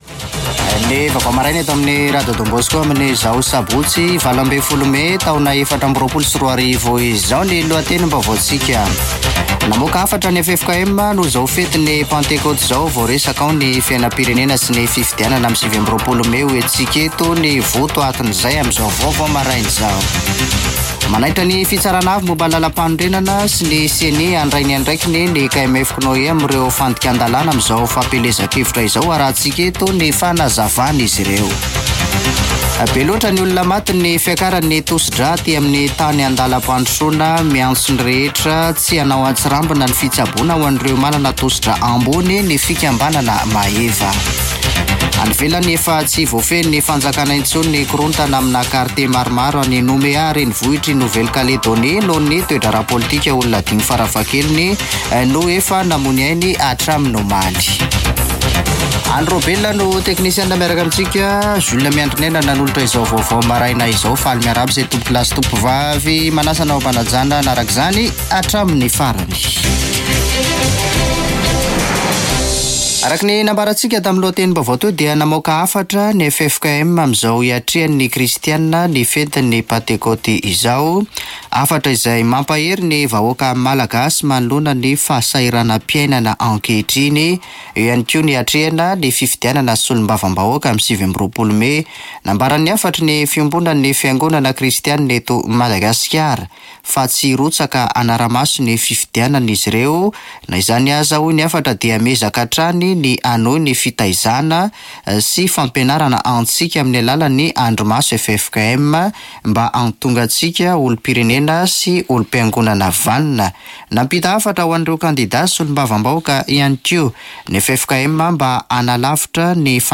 [Vaovao maraina] Sabotsy 18 mey 2024